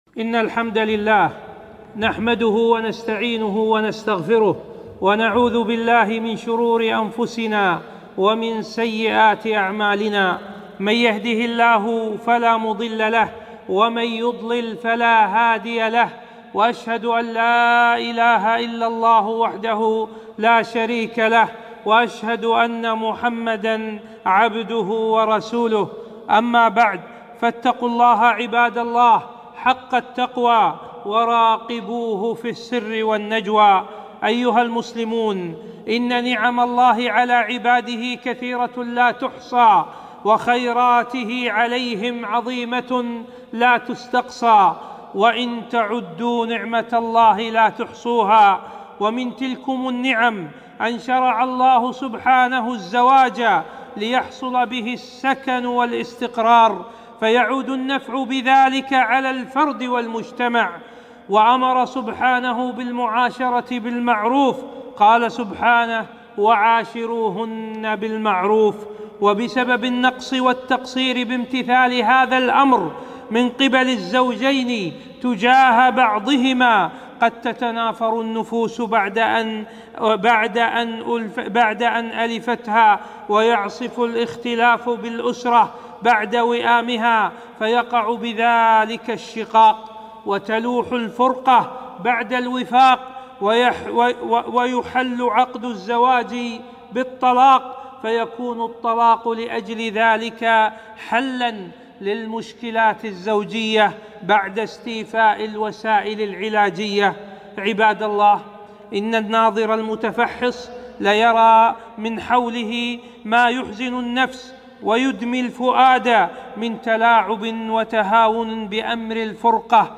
خطبة - مخالفات شرعية حول الطلاق